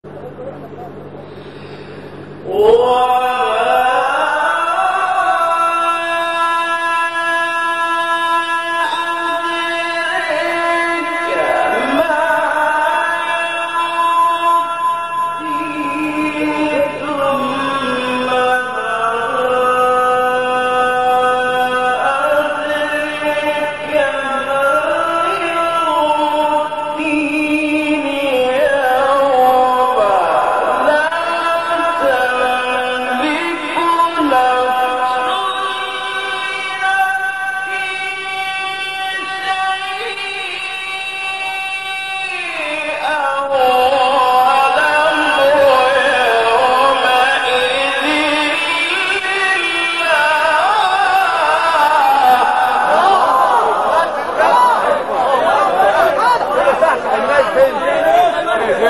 مقطع تلاوت زیبای استاد لیثی | نغمات قرآن | دانلود تلاوت قرآن